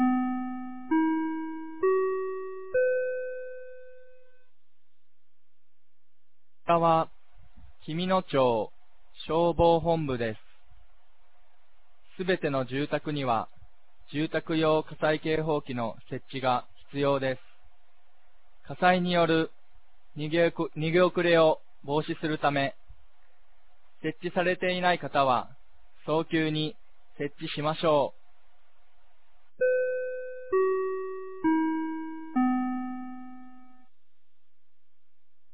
2026年04月04日 16時00分に、紀美野町より全地区へ放送がありました。